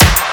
INSTCLAP06-R.wav